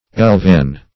Elvan \Elv"an\, a.